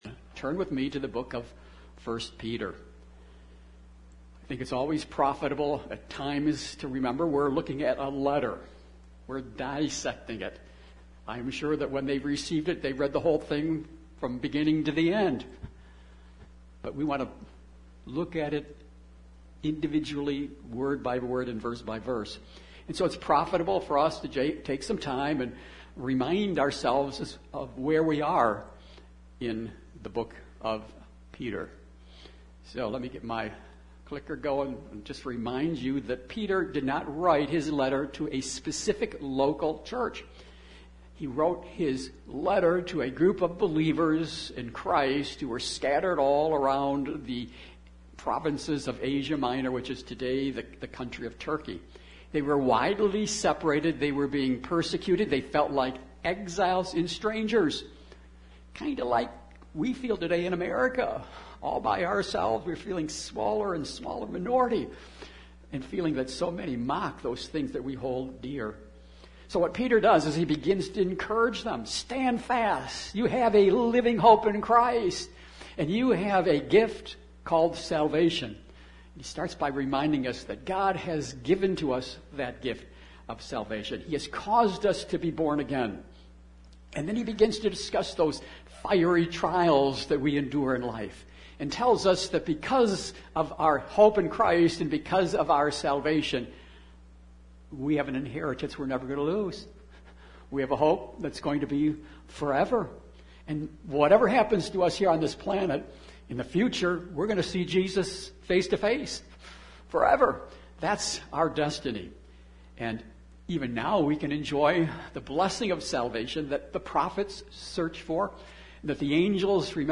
Jul 10, 2022 Living in the Fear of God MP3 SUBSCRIBE on iTunes(Podcast) Notes Sermons in this Series 1 Peter 1:17-21 Thank You, Peter!